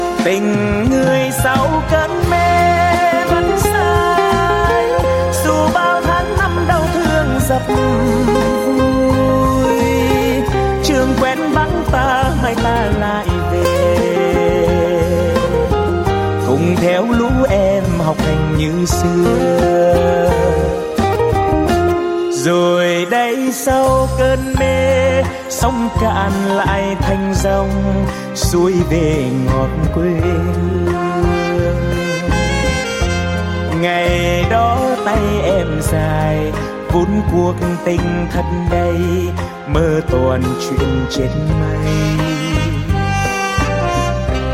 Thể loại: Bolero